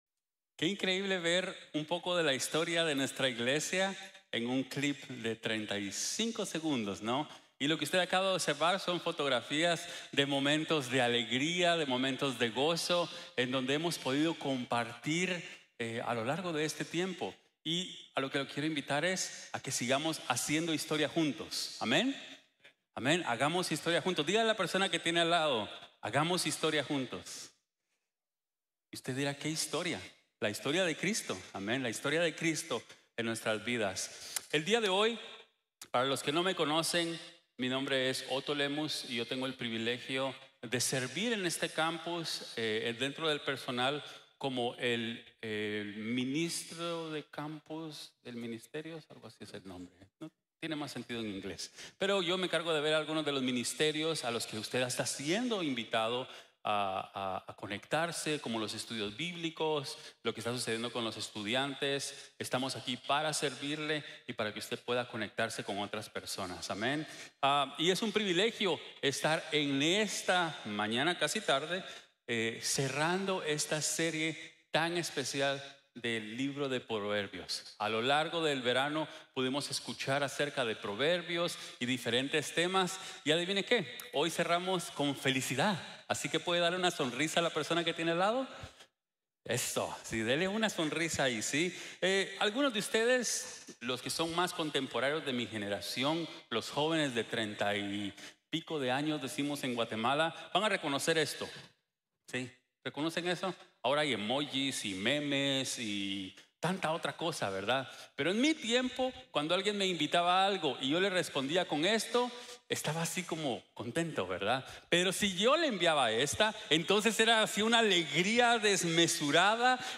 Midtown Campus